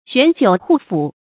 玄酒瓠脯 xuán jiǔ hù fǔ
玄酒瓠脯发音